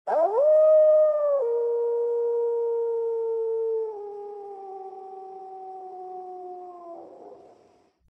audio-wolf.wav